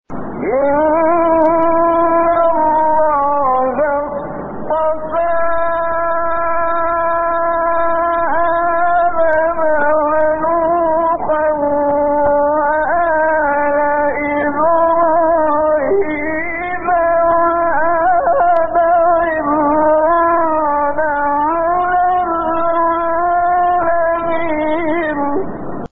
گروه شبکه اجتماعی: فرازهای صوتی از سوره آل‌عمران با صوت کامل یوسف البهتیمی که در مقام‌های مختلف اجرا شده است، می‌شنوید.
مقام صبا